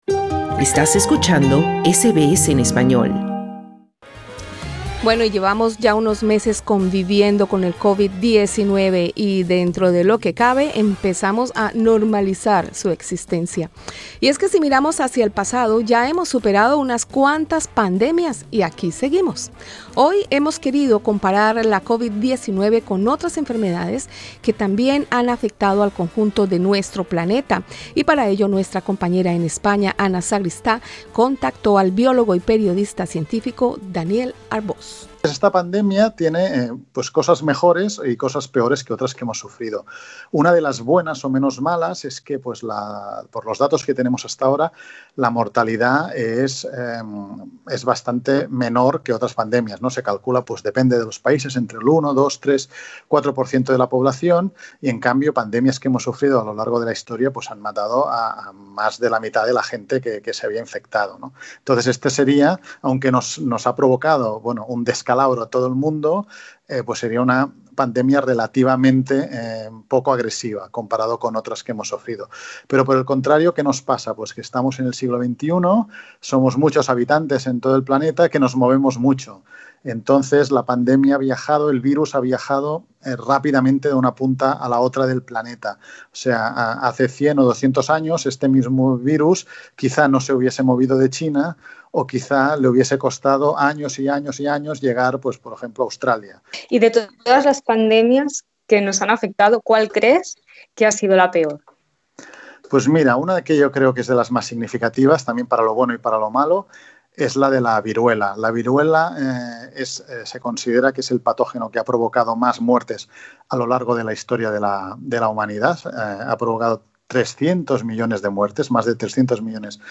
En entrevista con SBS español